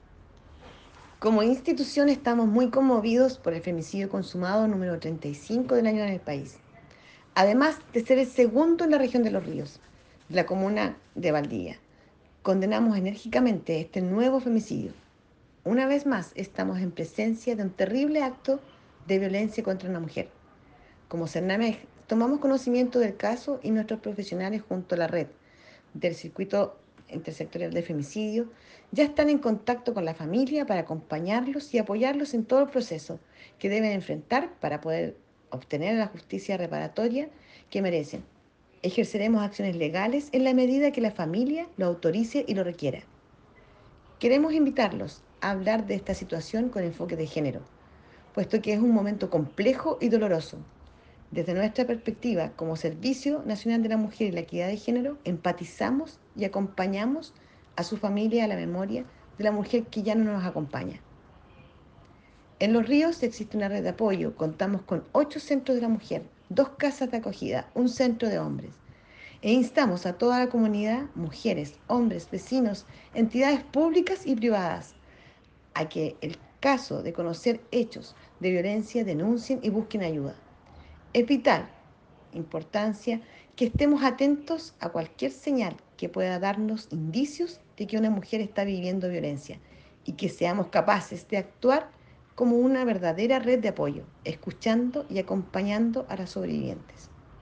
En un punto de prensa, la Seremi de la Mujer y la Equidad de Género, Francisca Corbalán Herrera, la Directora Regional de SernamEG, Waleska Fehrmann Atero y la Alcaldesa de Valdivia, Carla Amtmann Fecci, junto a sus equipos se refirieron al segundo femicidio consumado en la región.
CUÑA-DIRECTORA-REGIONAL-SERNAMEG-LOS-RÍOS-WALESKA-FEHRMANN-ATERO.mp3